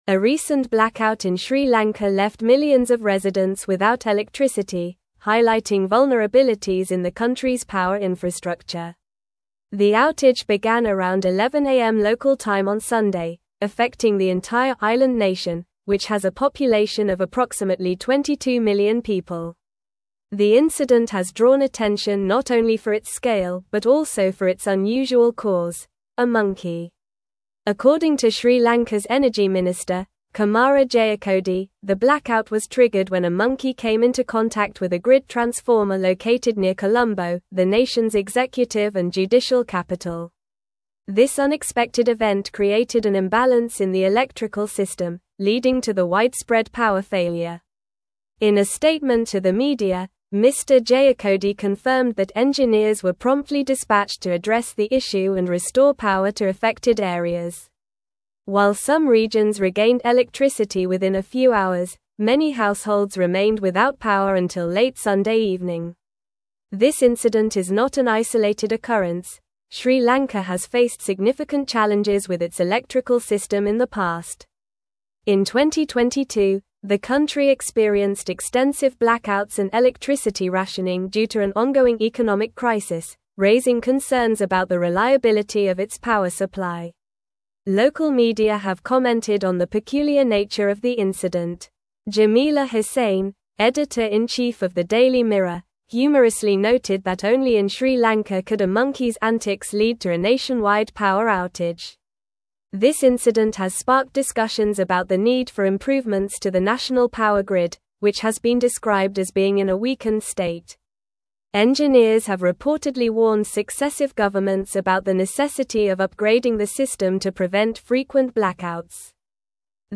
Normal
English-Newsroom-Advanced-NORMAL-Reading-Monkey-Causes-Nationwide-Blackout-in-Sri-Lanka.mp3